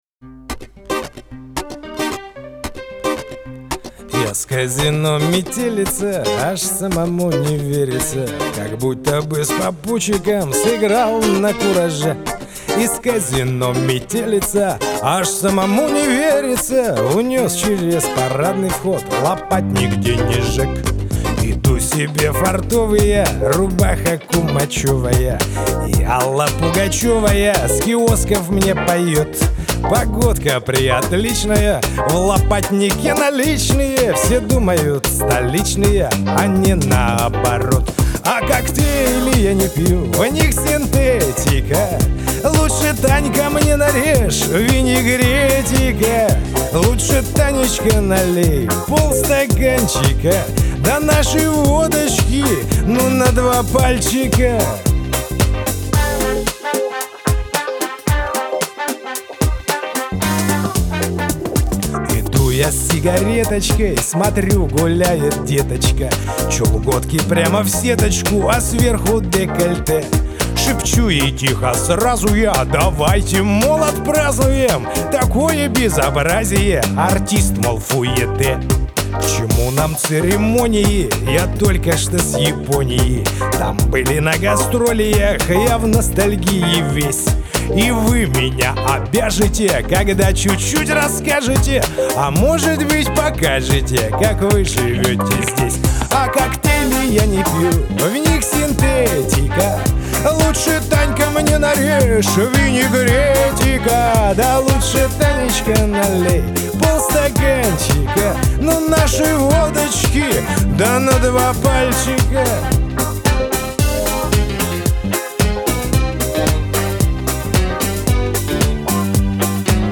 Скачать шансон